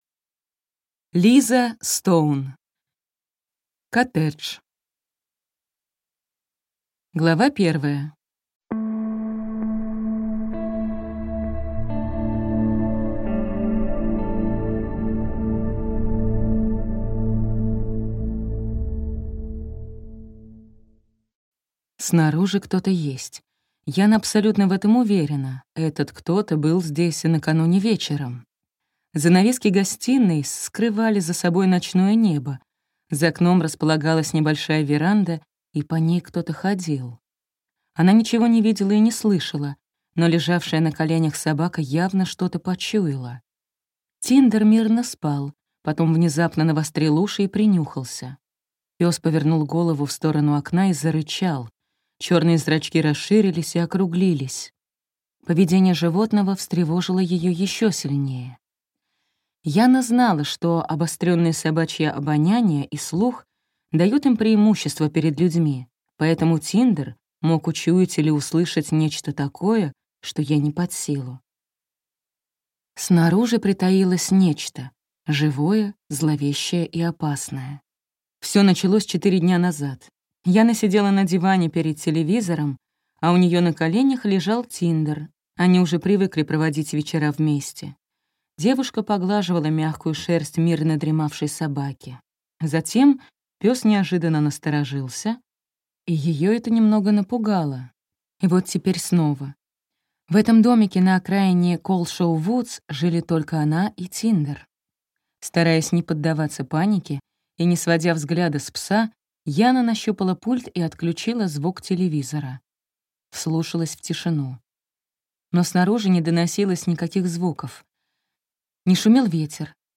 Аудиокнига Коттедж | Библиотека аудиокниг